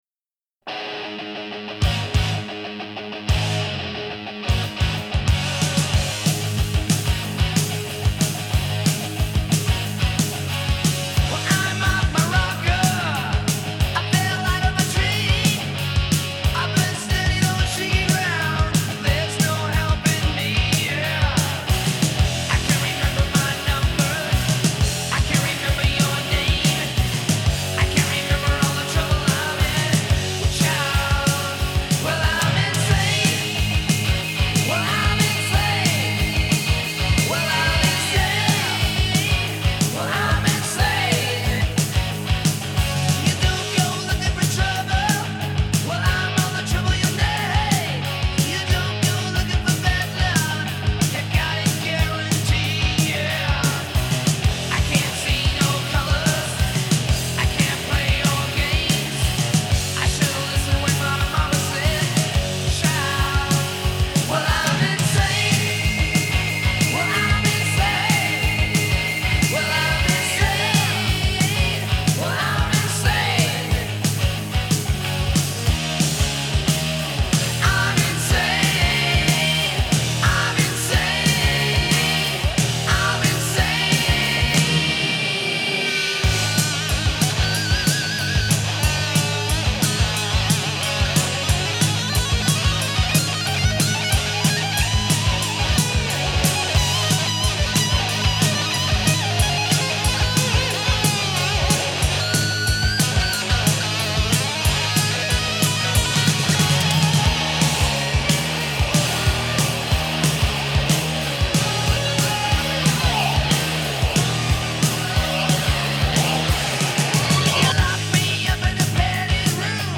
Глэм-металл